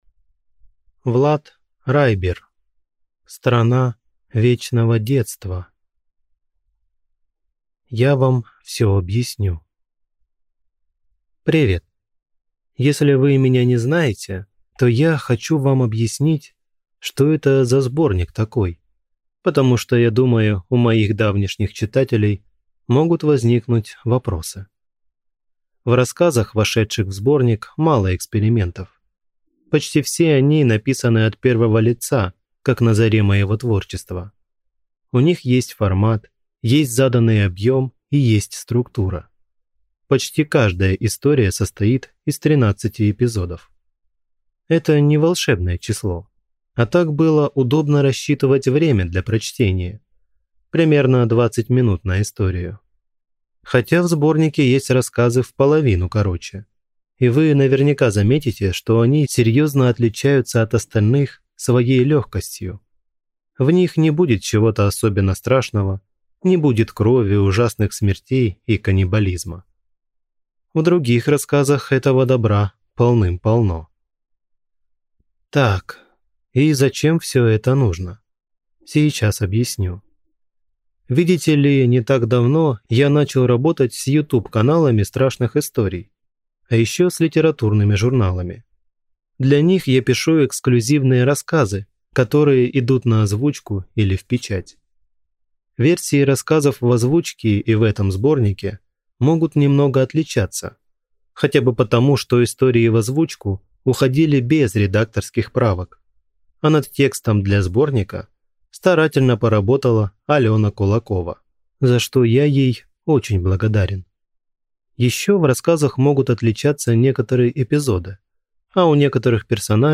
Аудиокнига Страна вечного детства | Библиотека аудиокниг